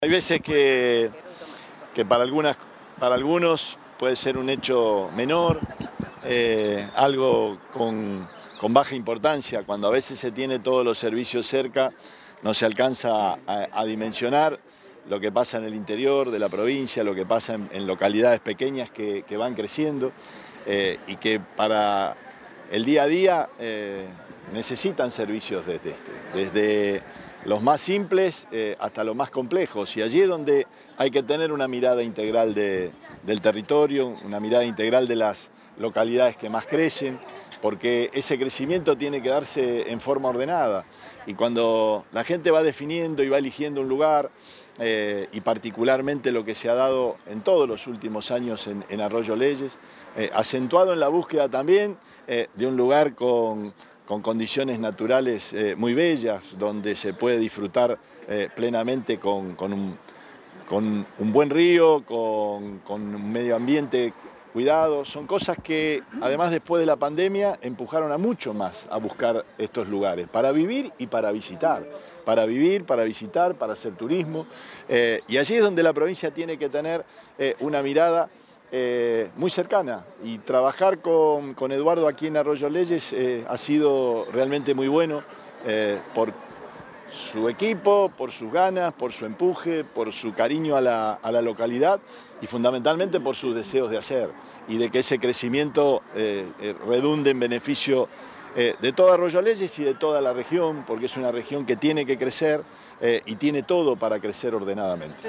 Perotti encabezó la inauguración del cajero en Arroyo Leyes.
Gobernador, Omar Perotti.